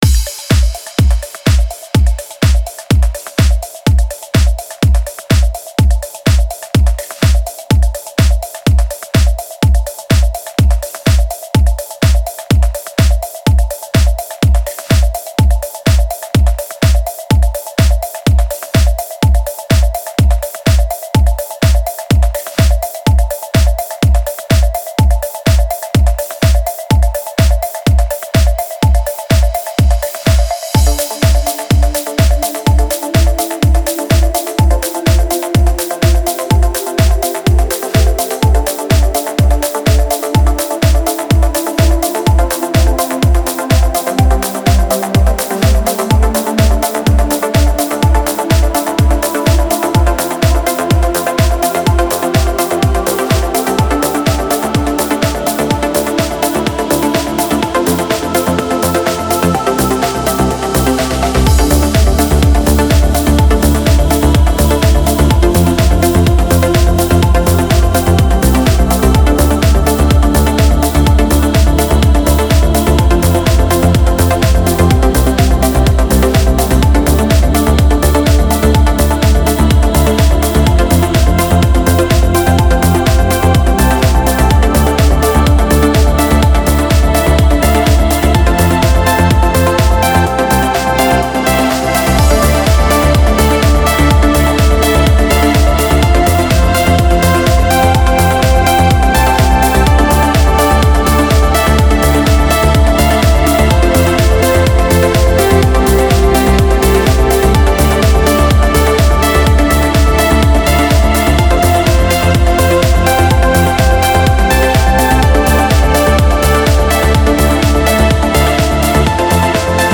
جلای روح با یک ملودیک ترنس (شاهکاری از دنیای موسیقی)واقعا بینظیرو استثنایی